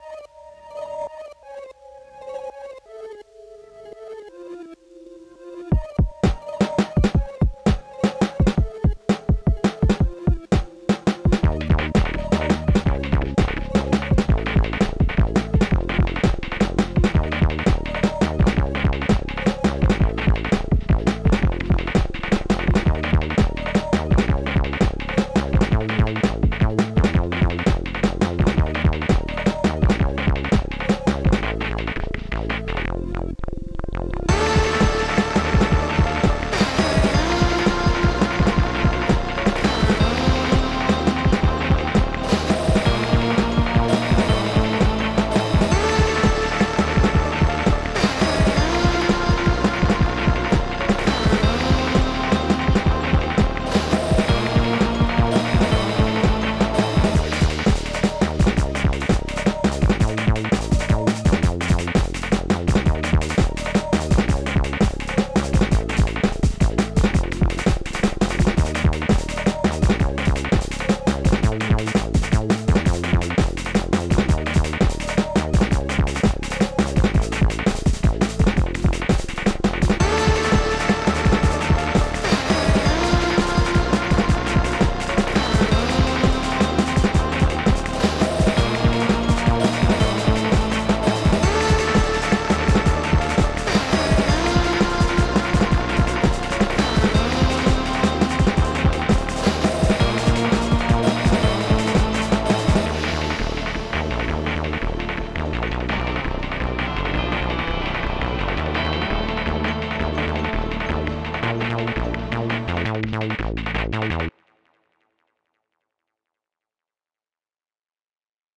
–snare, hats, and crash from Orange Tree’s JazzFunk Kit
–Fake 303 from M8’s in-engine synth (Saw Square + resonant lowpass)
Mix and master are bad, with the amen and bassline frequently drowned out and the open hat getting really harsh. It’s also too repetitive, even under 2 minutes, and the outro sample is out of sync with the rest of the track. I do overall like my bassline, melodies, and the layered kick-snare pattern.